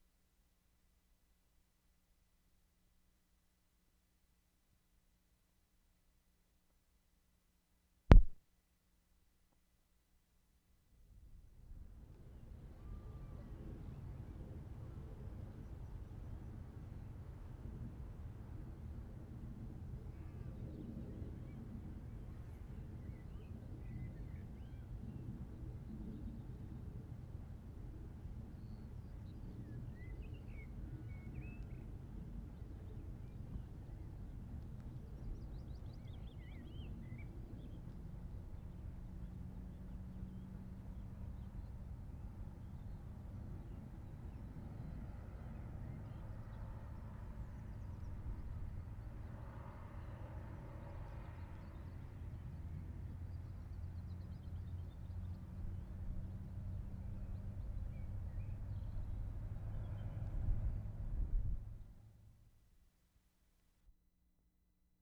Pennine Way, England May 13/75
TORSIDE WATER CASEMENT AREA (RESERVOIR)
mark * phasing effect when recordist moves away from large outfall. [4:38]
mark * ambience on the dam. [5:46]
1, 2. At beginning water is gushing out of reservoir in rhythmic patterns. Recordist moves to larger outfall and then away. Note (at mark *) the pronounced phasing effect as the recordist moves away.